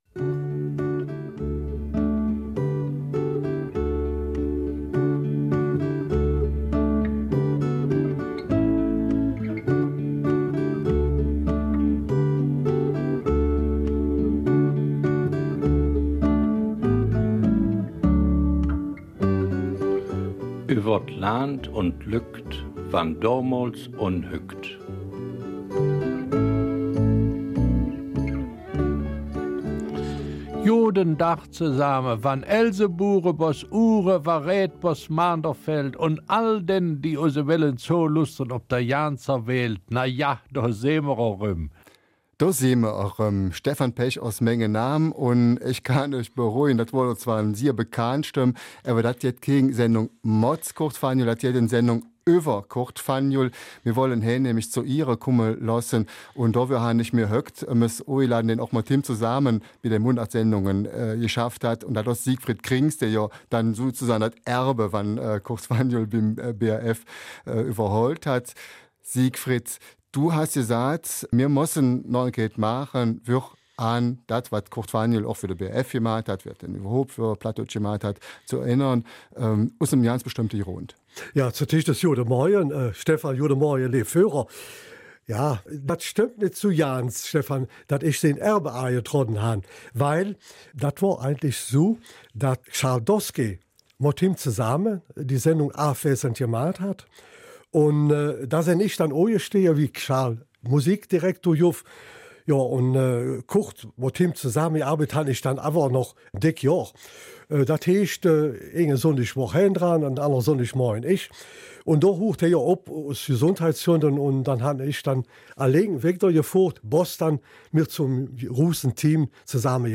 Eifeler Mundartsendung
Eifeler Mundart